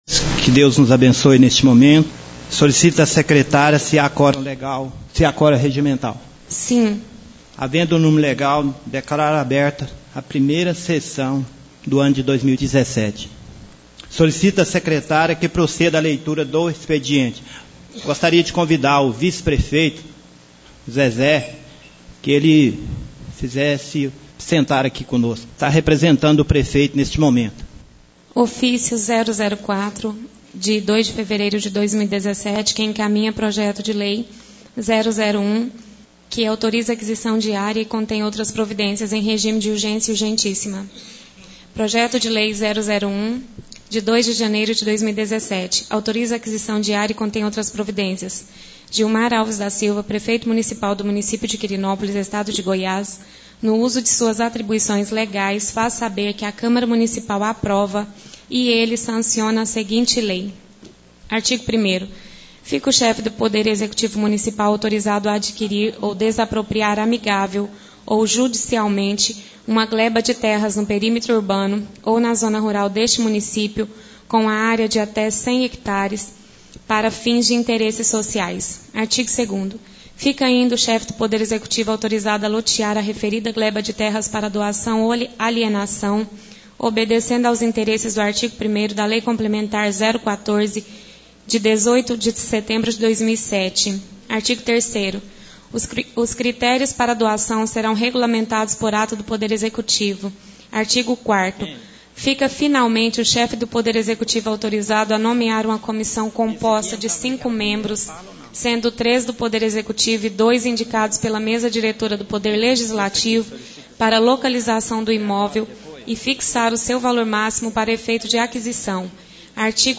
Primeira Sessão Ordinária da Câmara Municipal Fevereiro